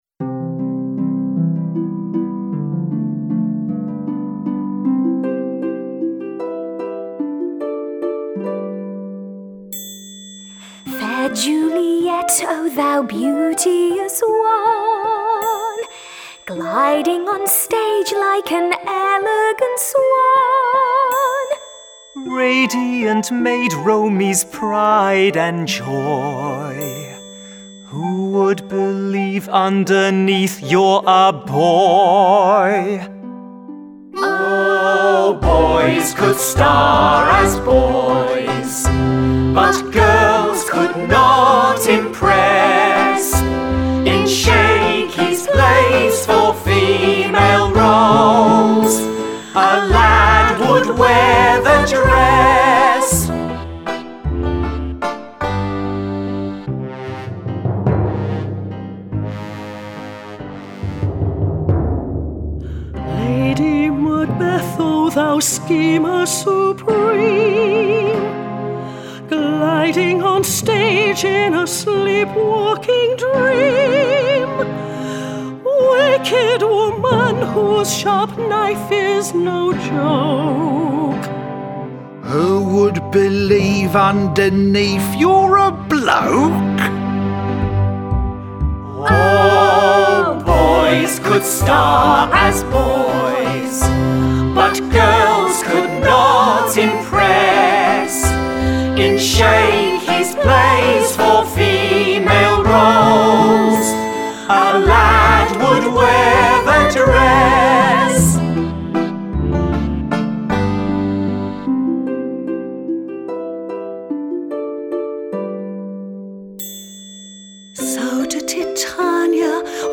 Download full vocal